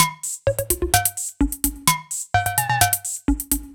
Index of /musicradar/french-house-chillout-samples/128bpm/Beats
FHC_BeatD_128-02_Tops.wav